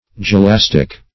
Search Result for " gelastic" : The Collaborative International Dictionary of English v.0.48: Gelastic \Ge*las"tic\, a. [Gr.